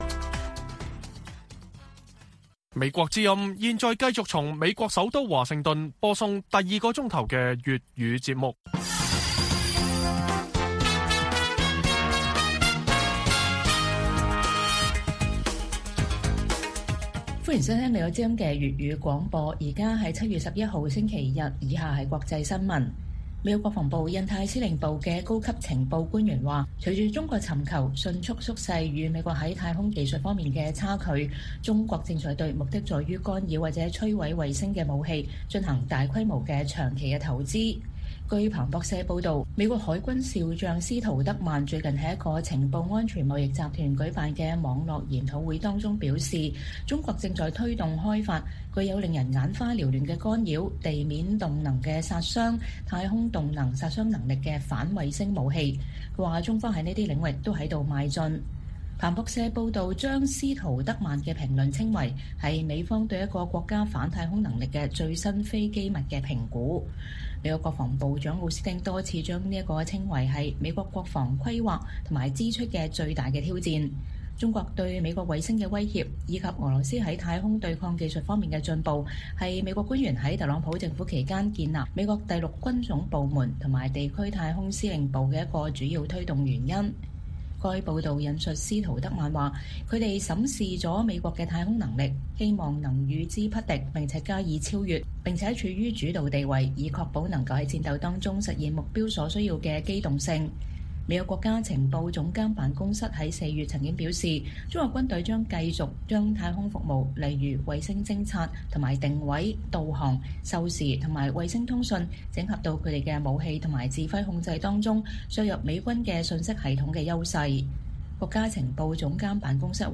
粵語新聞 晚上10-11點： 五角大樓：中國的攻擊性太空技術“正在進行中”